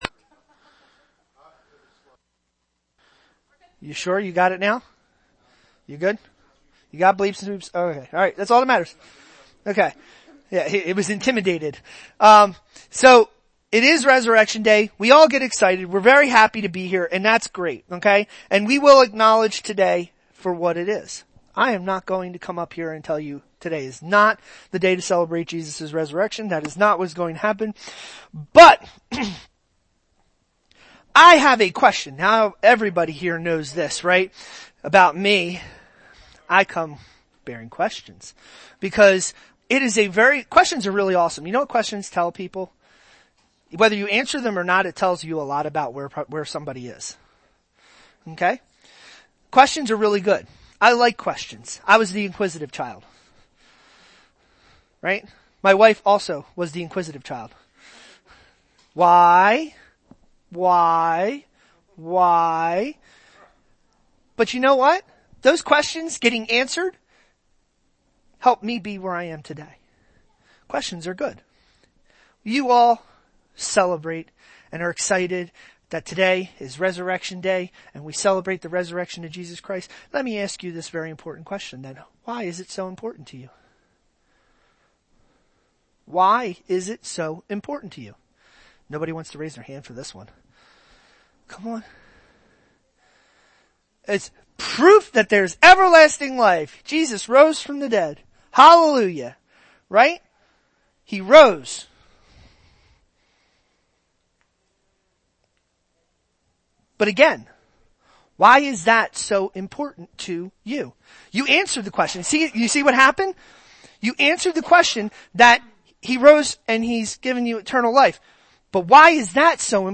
Series: Sunday Service
Revelation 1:18 Service Type: Sunday Happy resurrection day!